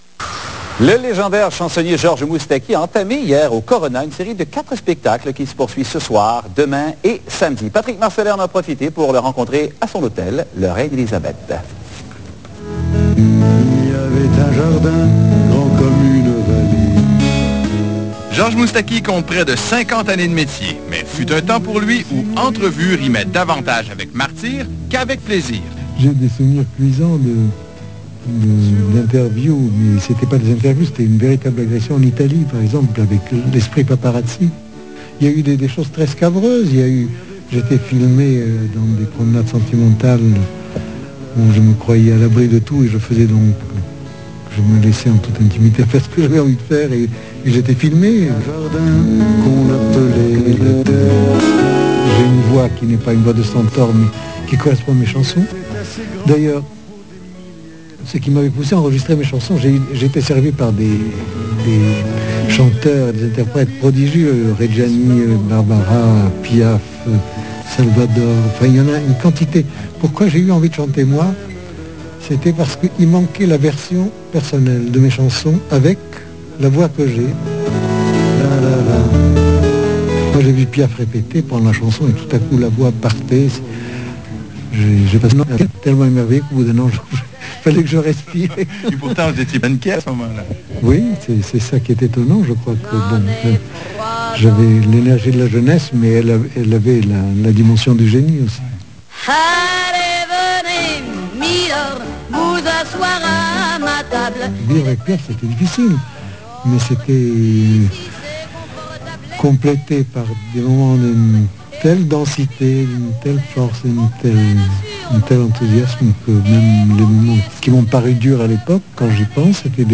Interview de Georges MOUSTAKI pour la Télévision Quatre-Saisons Québeçoise.